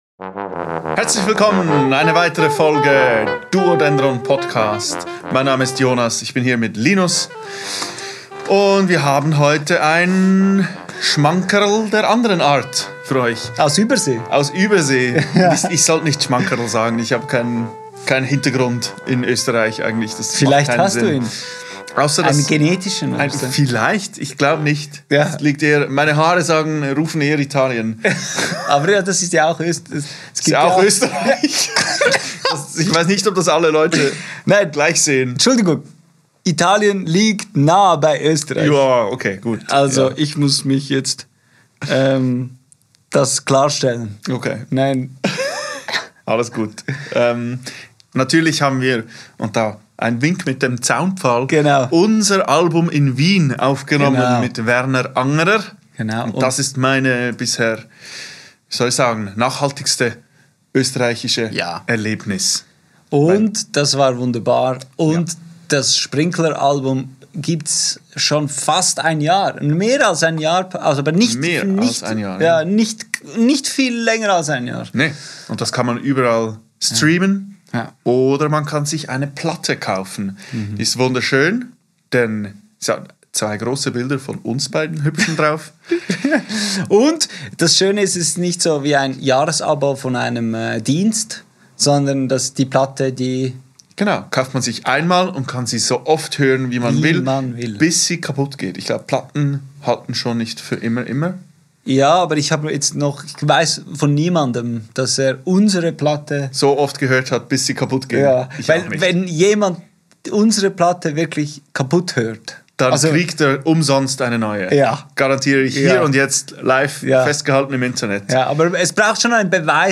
Heute gibt es Jazz, Gespräche über Zweit- bis Dritt- oder sogar Viertinstrumente und darüber, dass man nicht immer so streng mit sich sein sollte.